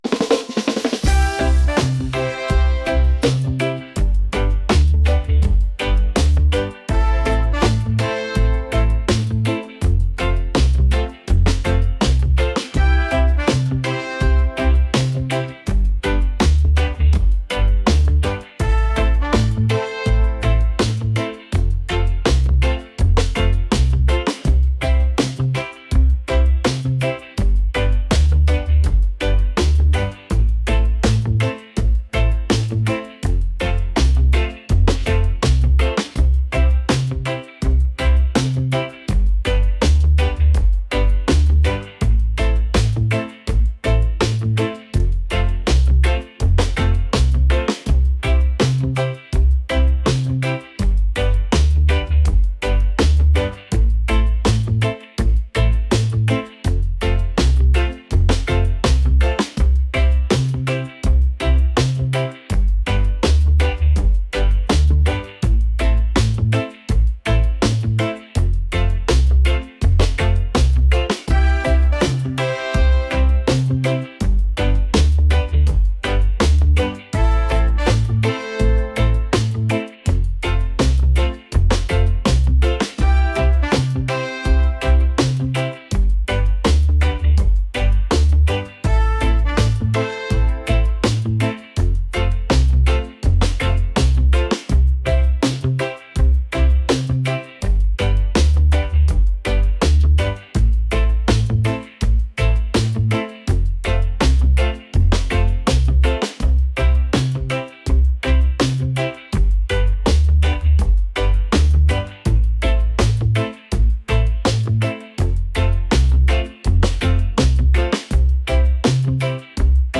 upbeat | reggae | energetic